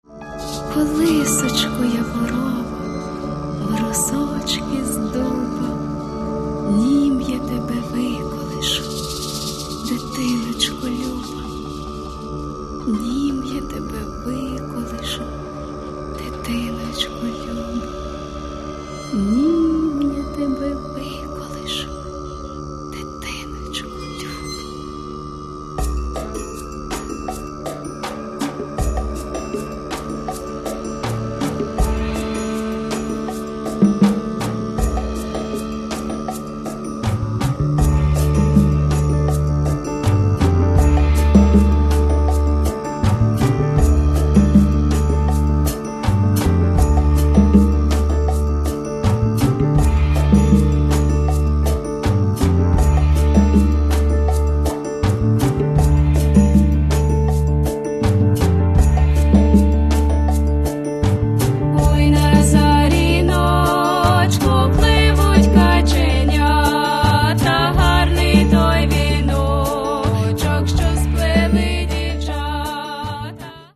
Каталог -> Джаз и около -> Этно-джаз и фольк